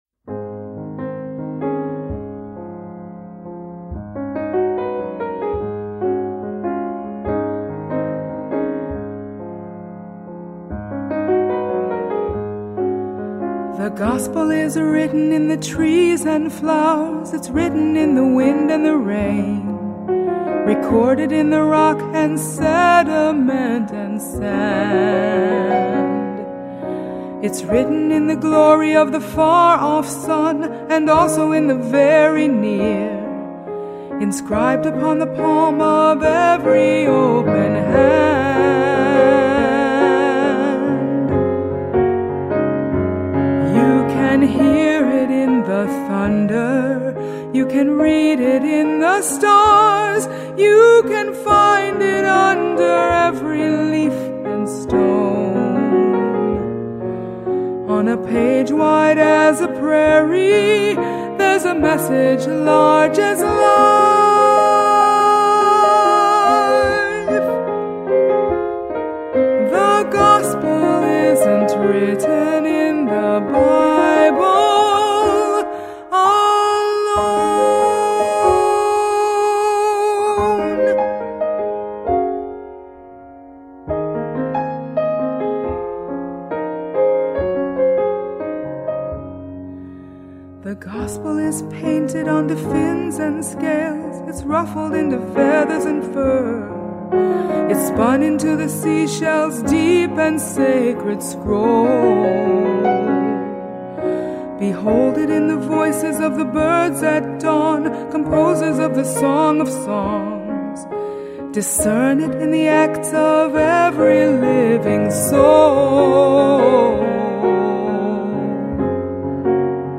Medium voice, piano